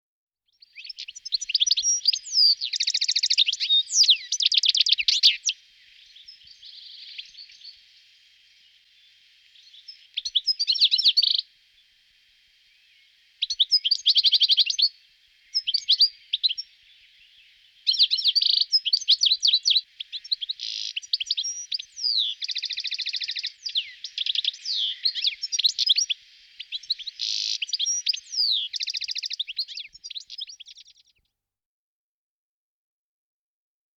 die genannten und abgebildeten Vögel sind im Park anzutreffen
Distelfink Stiglitz
Stieglitz.mp3